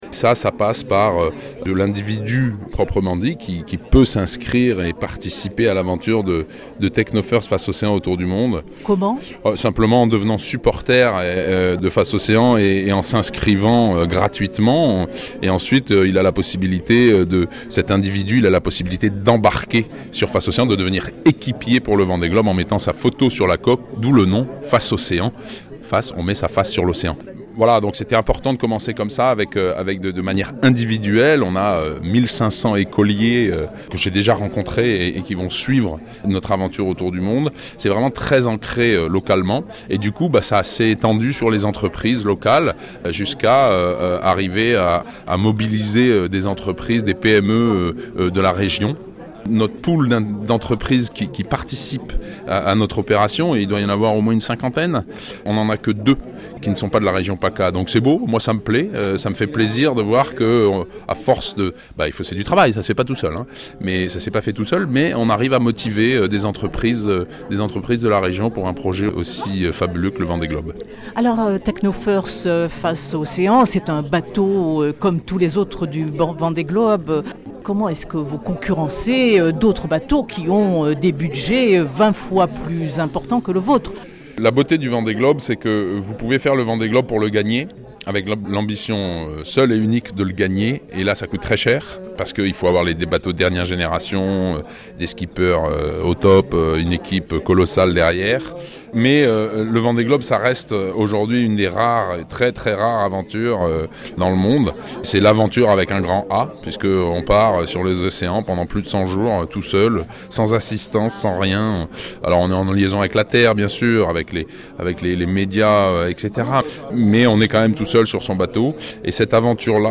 Sébastien Destremau revient sur le Vendée Globe